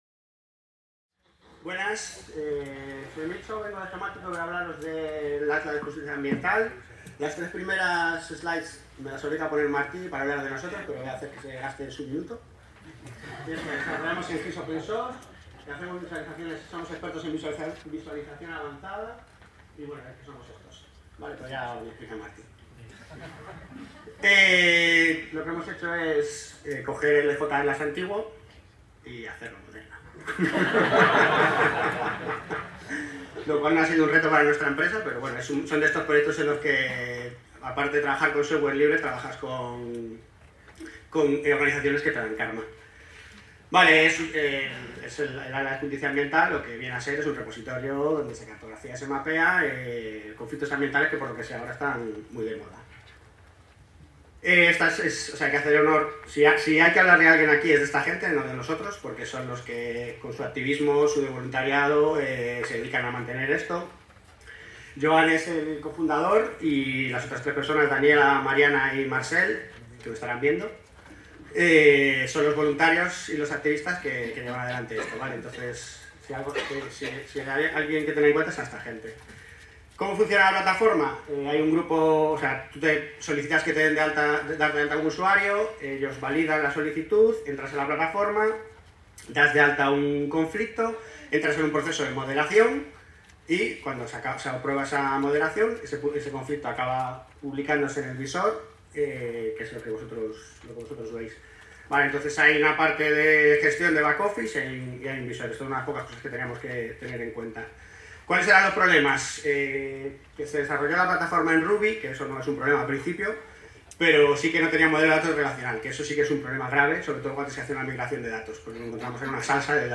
Comunicació
en el marc de les 18enes Jornades de SIG Lliure 2025 organitzades pel SIGTE de la Universitat de Girona. S'explica el funcionament de El Environmental Justice Atlas (EjAtlas) que és una plataforma digital de codi obert per mapejar conflictes socioambientals arreu del món.